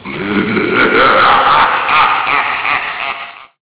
evillaugh.ogg